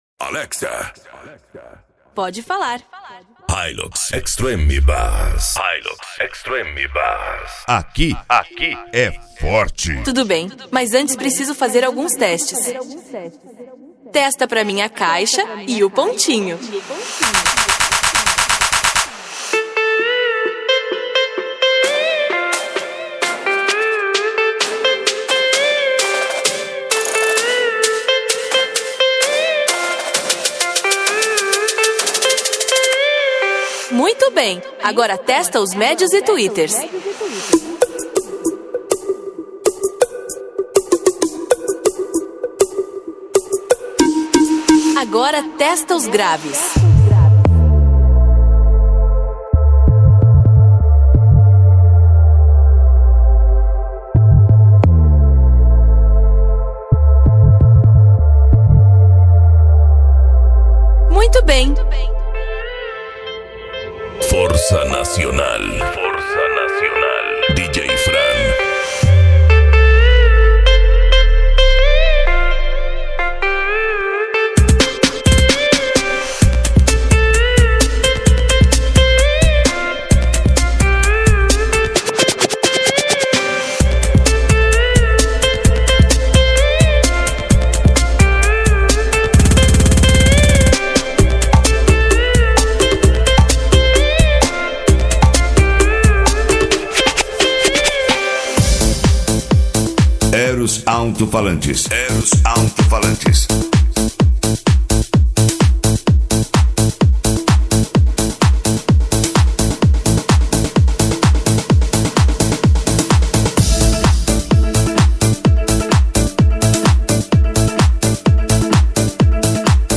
Bass
Eletronica
Remix